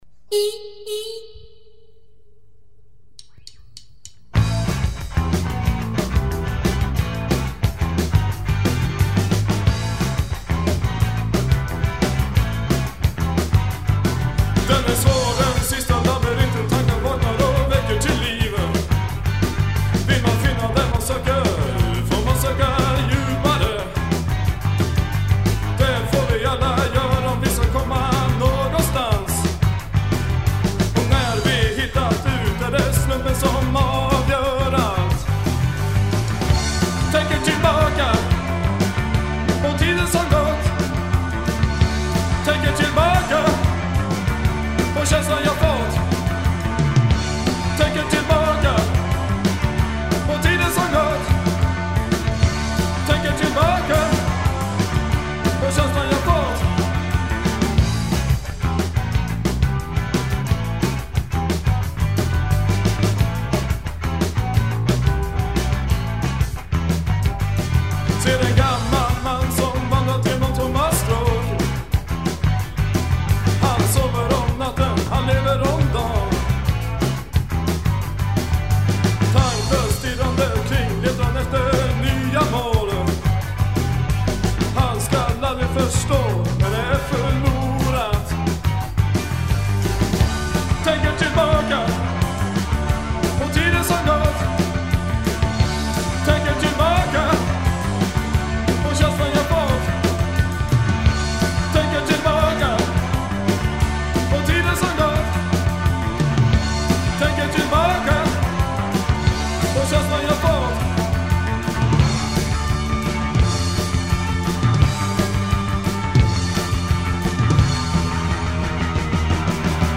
Bass
Guitar
Voice
Drums